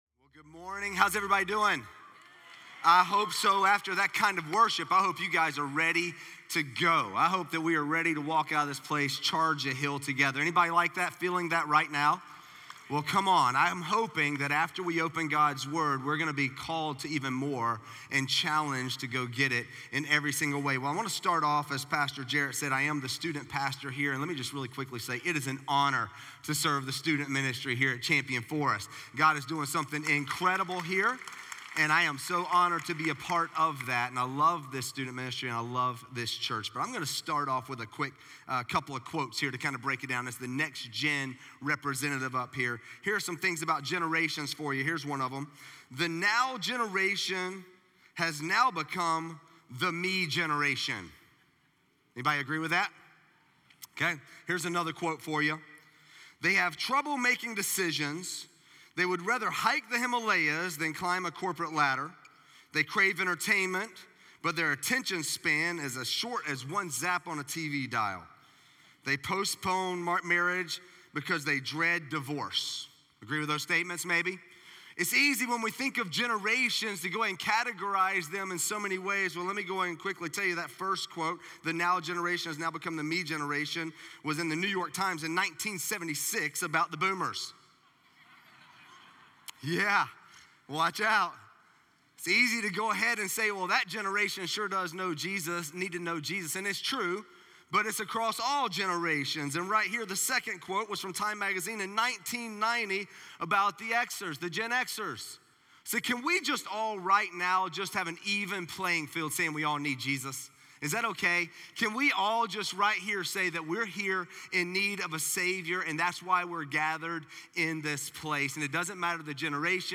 Message: “Our Sustainer